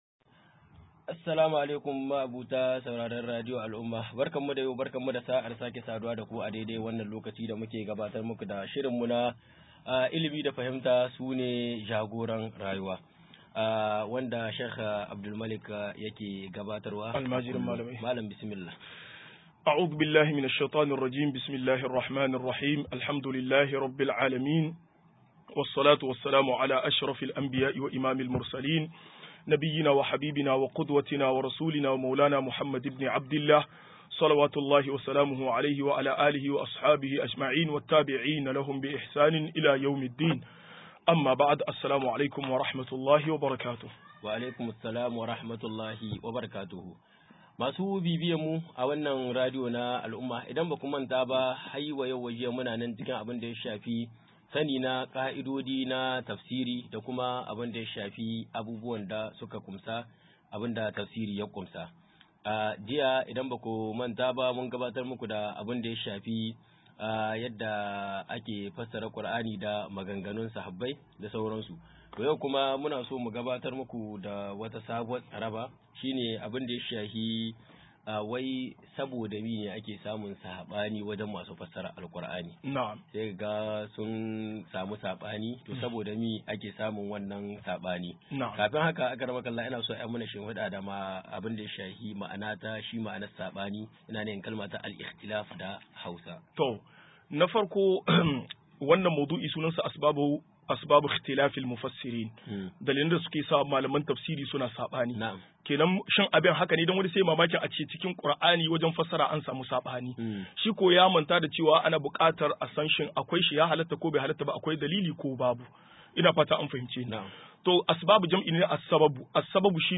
190-Sabubban Sabani a cikin Tafsiri - MUHADARA